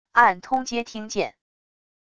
按通接听键wav音频